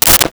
Sword Whip 02
Sword Whip 02.wav